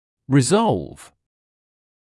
[rɪ’zɔlv][ри’золв]разрешать, разрешать (проблему, задачу)